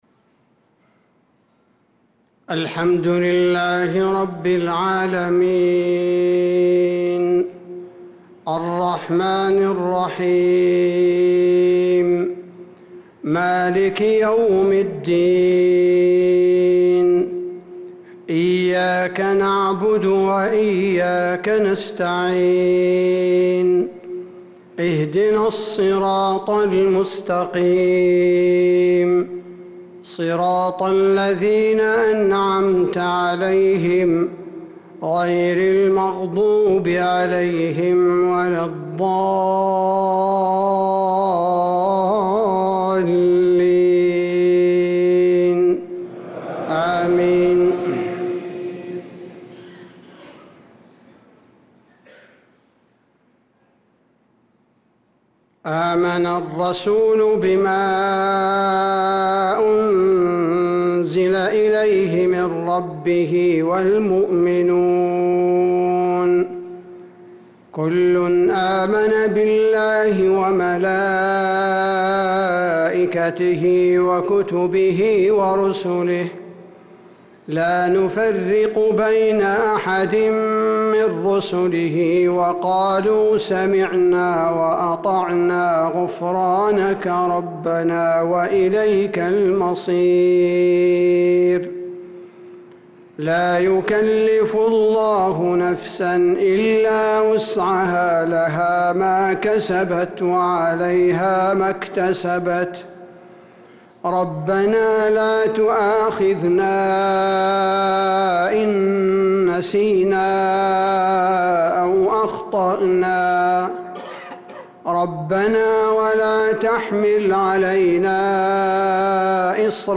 صلاة المغرب للقارئ عبدالباري الثبيتي 24 شوال 1445 هـ